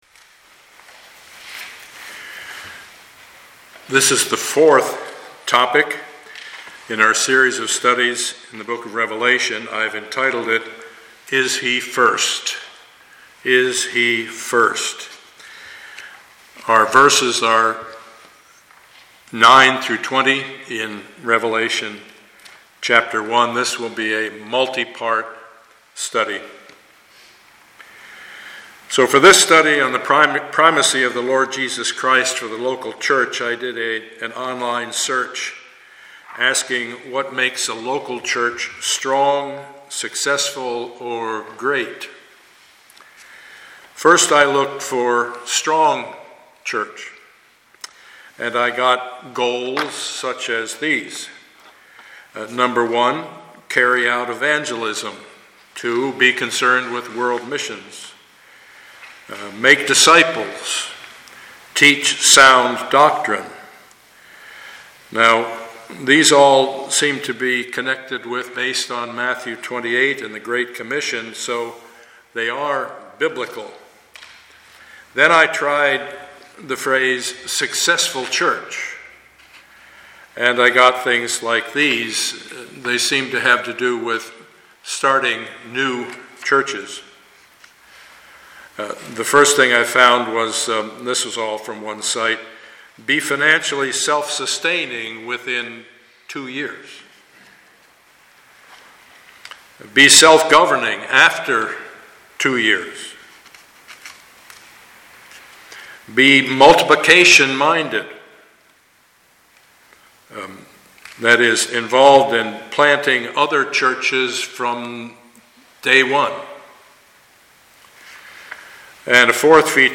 Passage: Revelation 1:9-20 Service Type: Sunday morning